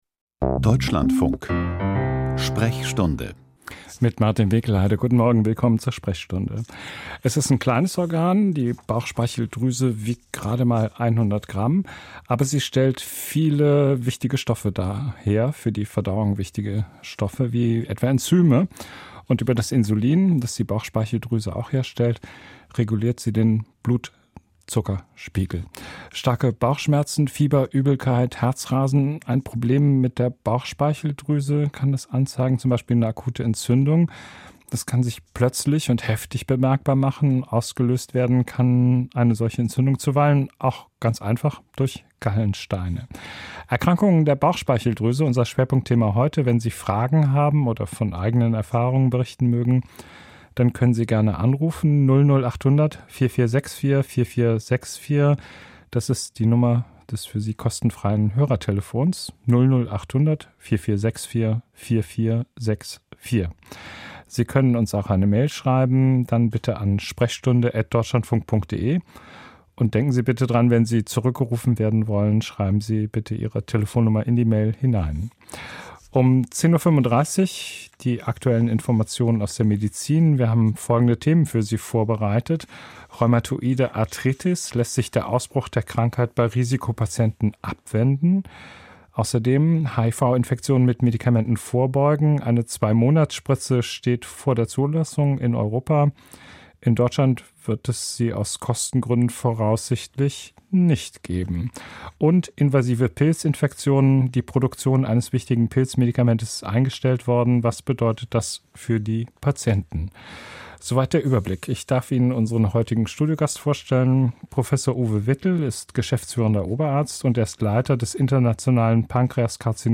Erkrankungen der Bauchspeicheldrüse - Studiogespräch